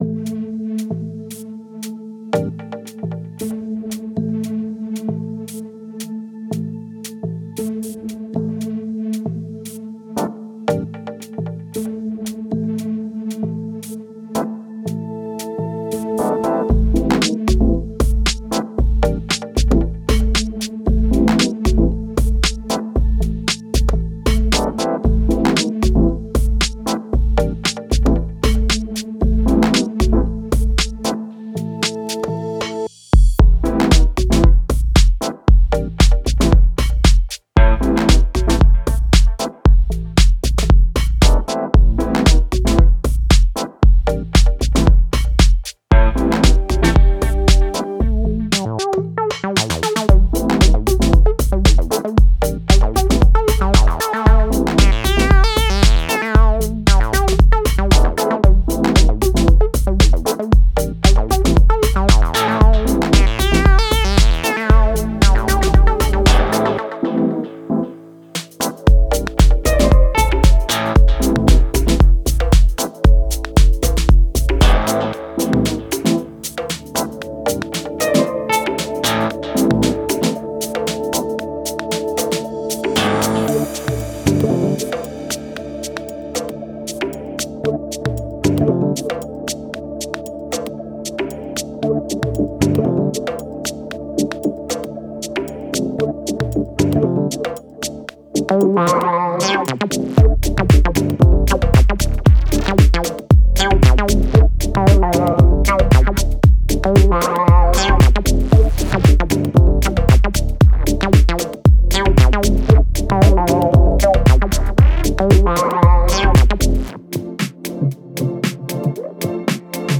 Genre:House
ミニマルでありながらパンチの効いた、エレガントでありながら力強さを秘めたそのサウンドは、まさに紳士のための音楽。
Tempo - 115 BPM
Acid Loops – 50